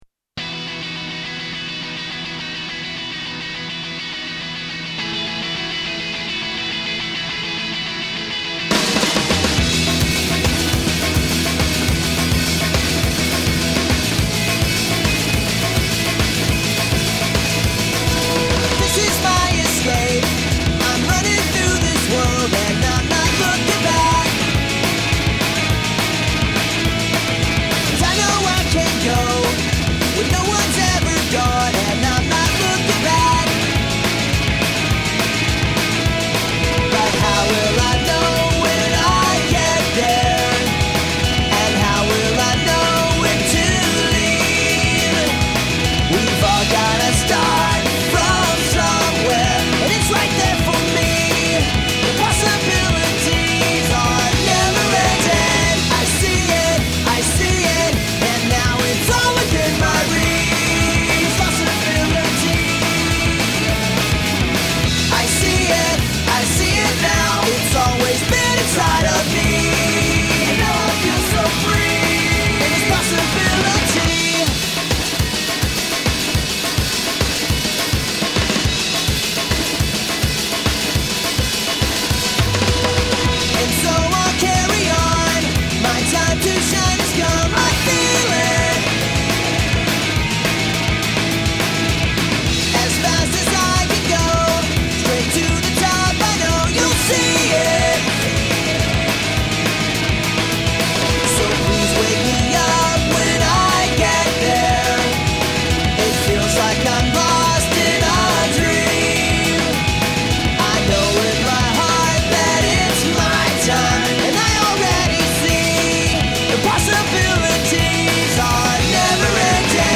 Ïîñëóøàòü ñ âîêàëîì